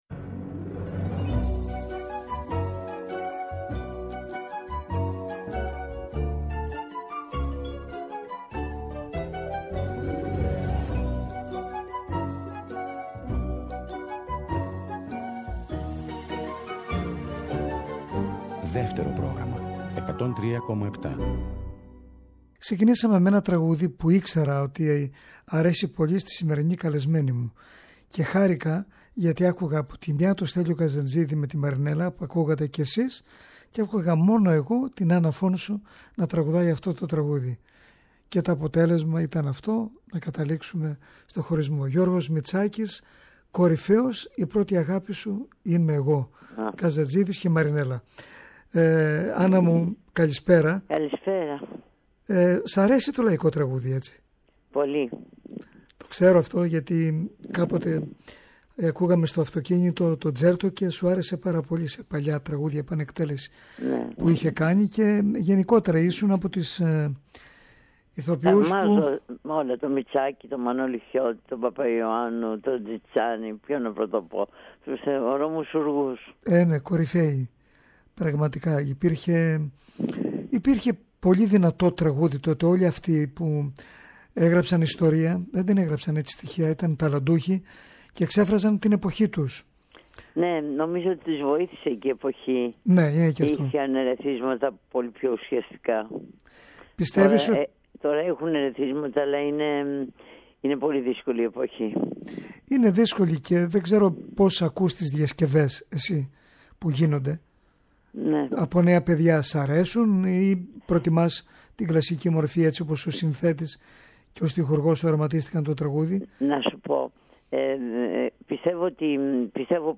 σε μια εφ’ όλης της ύλης συνέντευξη.